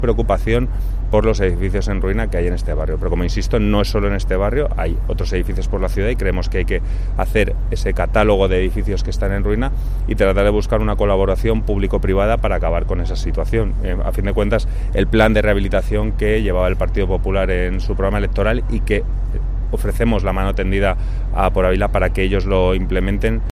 Además, el Partido Popular ha explicado hoy que en la comisión de urbanismo de Noviembre pasaron un listado de 8 edificios en ruina en la zona y que meses después el Equipo de Gobierno del Ayuntamiento no ha hecho nada, como ha analizado Jorge Pato, portavoz del PP en el Ayuntamiento de Ávila.